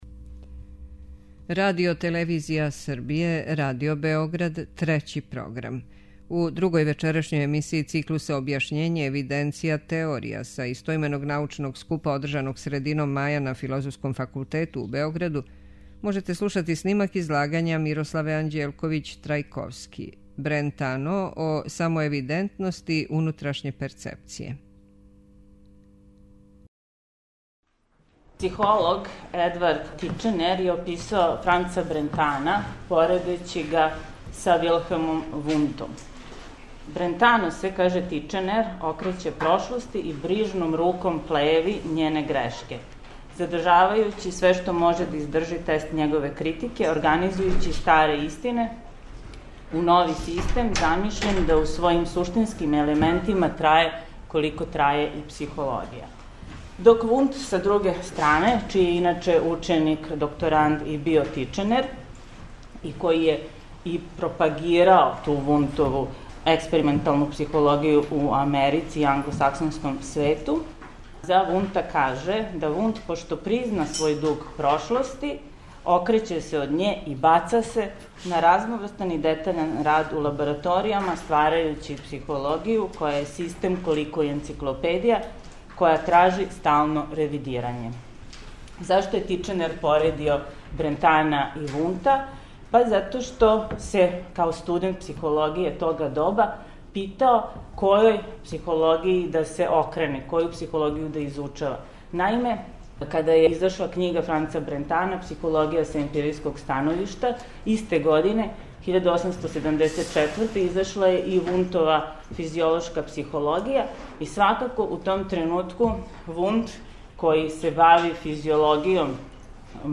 Дводневни научни скуп Објашњење, евиденција, теорија друга је интердисциплинарна конференција посвећена проблему и појму објашњења (да подсетимо, претходна, под називом Структура објашњења, одржана је крајем 2012. године, а у нашем часопису објављен је тематски блок текстова насталих на основу излагања са те конференције).